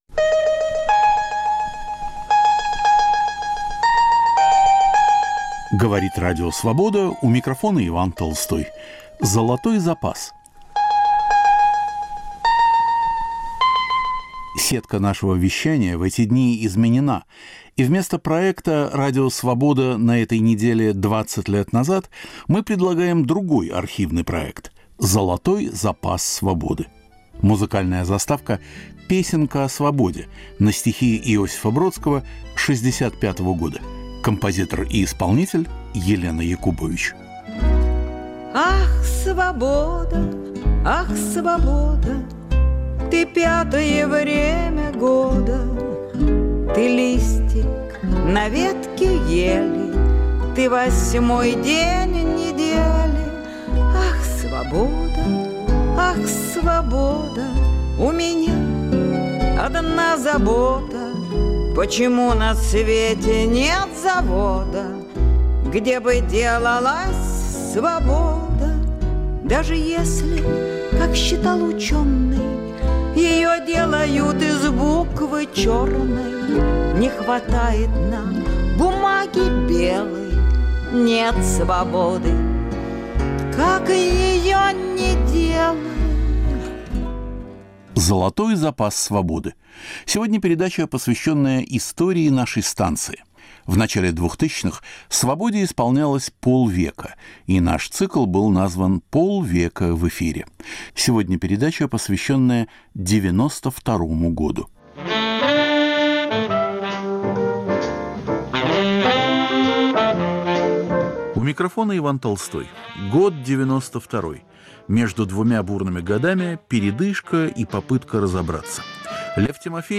К 50-летию Радио Свобода. 1992, архивные передачи: Лев Тимофеев о горбачевском политическом наследии, Абдурахман Авторханов - исторические аналогии, Арсений Рогинский - реформирован ли КГБ? Провал суда над КПСС. Югославская трагедия.